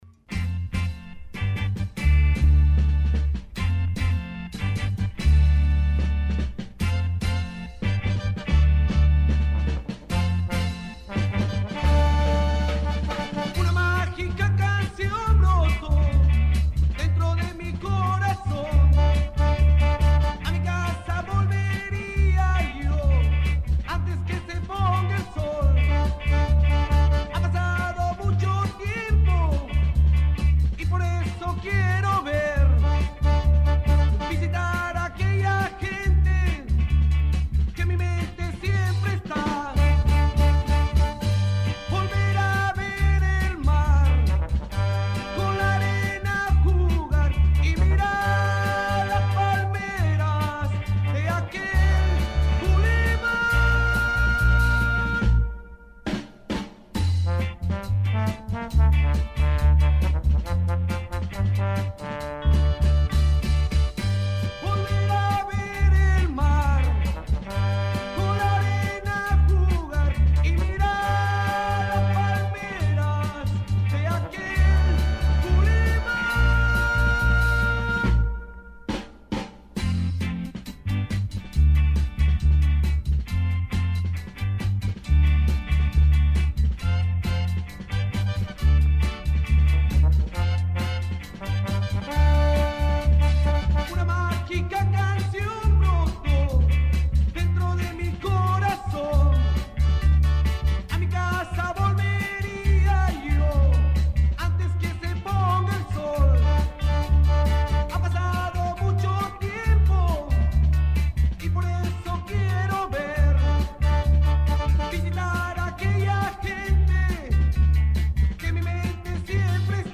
Escuche la columna completa: Descargar Audio no soportado